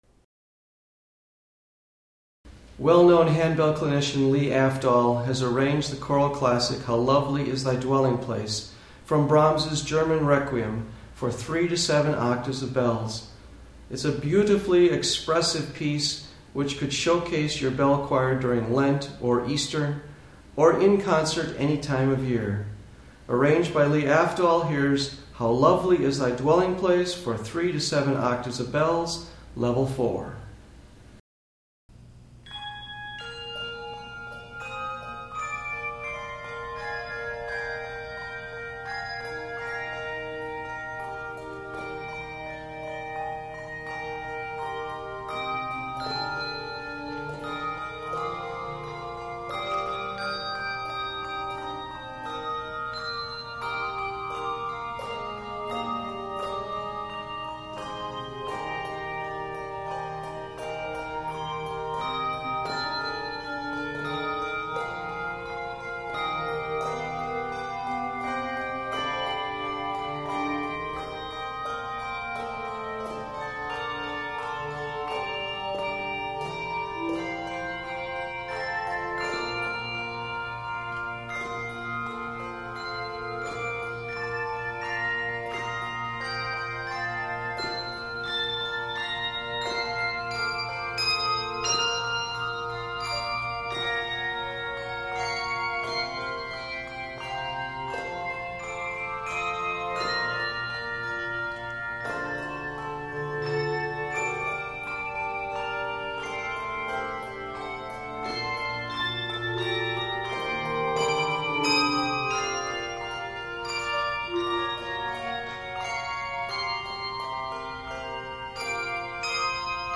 Voicing: Handbells 3-7 Octave